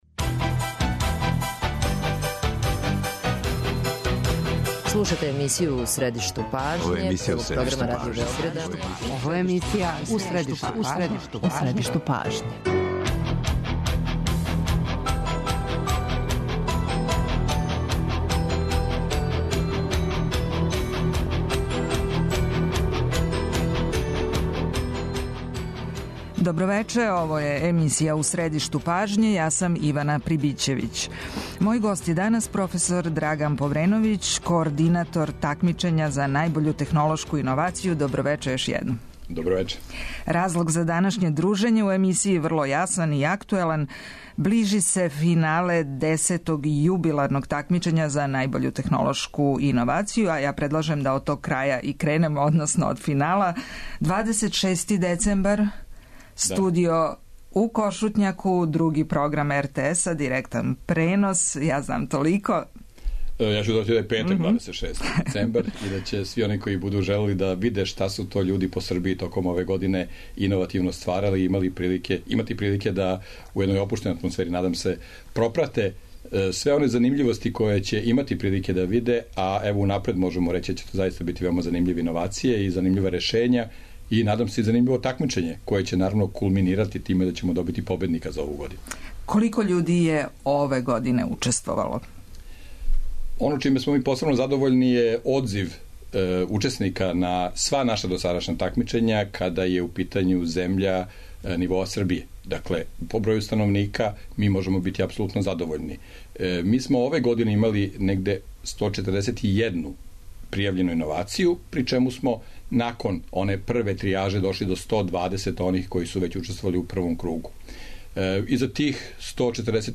Путем телефона у разговор ће се укључити организатори Такмичења из Новог Сада и Републике Српске, новинари који годинама прате овај пројекат и чланови жирија.